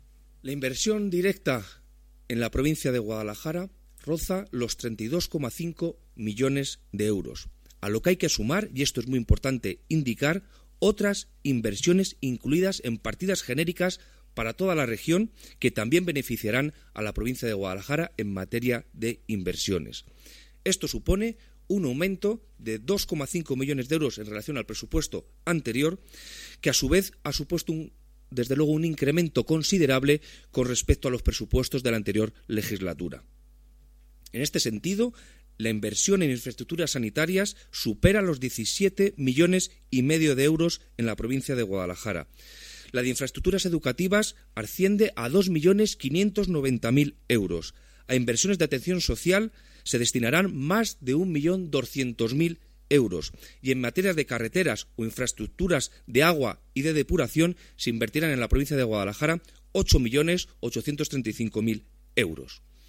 El delegado de la Junta en Guadalajara, Alberto Rojo, habla de las inversiones que el proyecto de presupuestos 2018 contempla en la provincia.